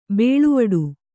♪ bēḷuvaḍu